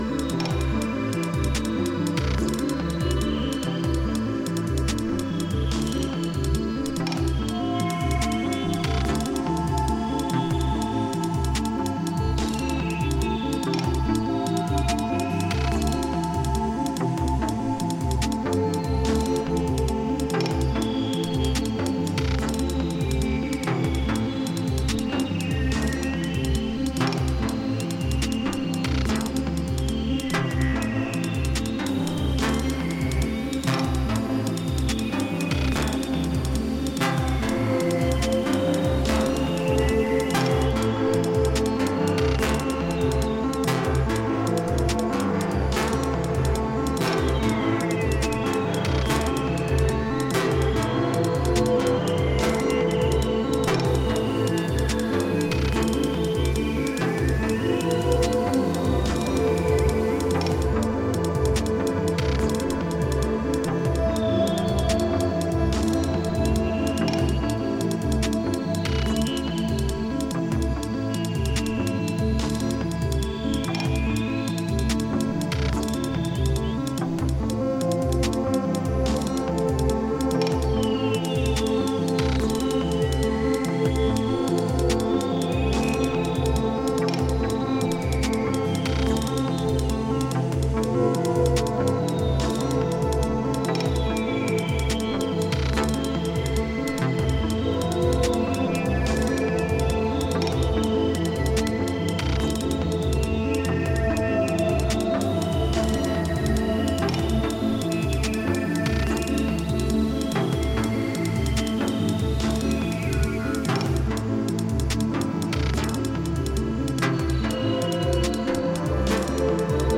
IDM/Electronica
mind-bending IDM version